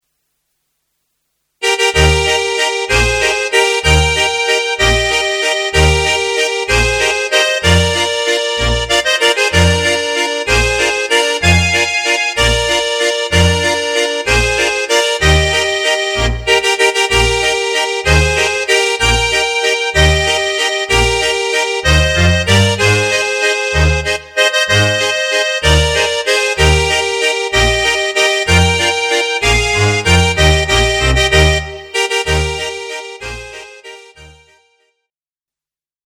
Eb-Dur